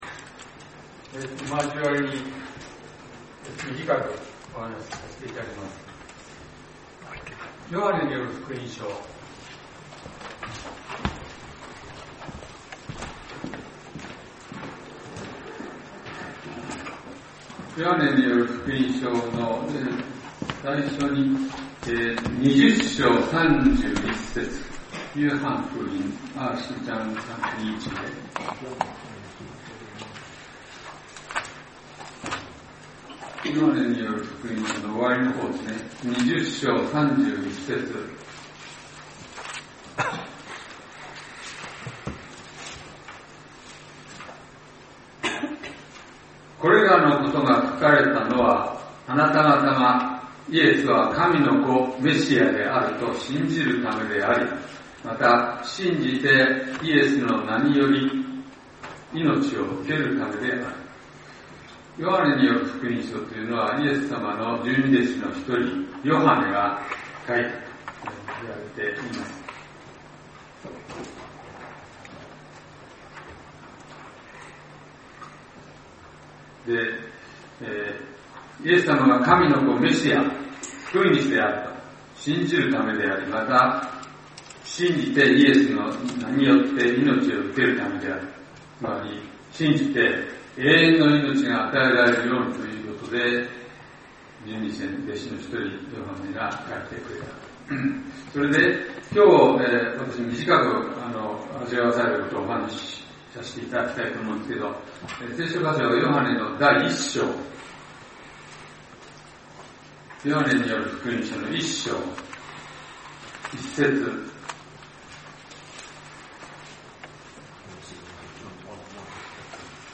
先週，東京集会で行わ れた礼拝で録音された建徳です。